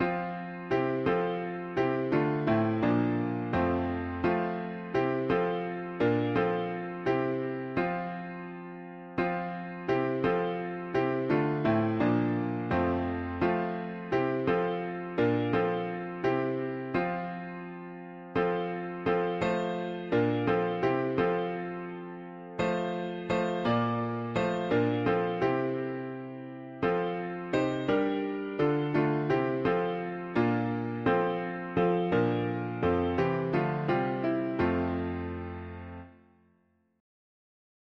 Speak unto J… english christian 4part
Key: F major